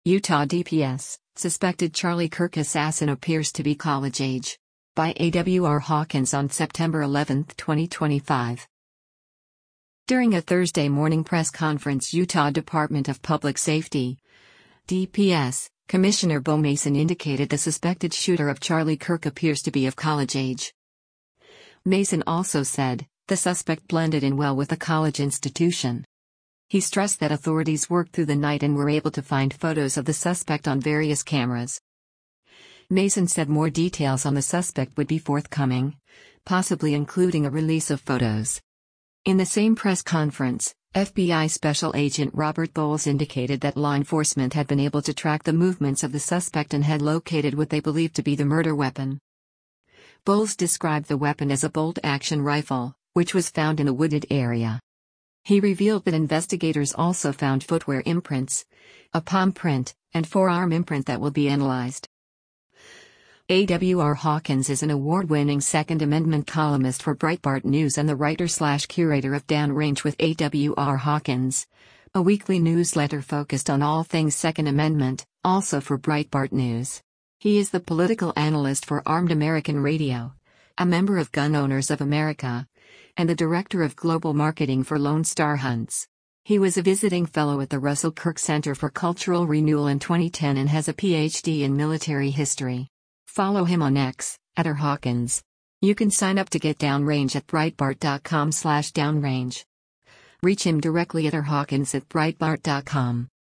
During a Thursday morning press conference Utah Department of Public Safety (DPS) Commissioner Beau Mason indicated the suspected shooter of Charlie Kirk “appears to be of college age.”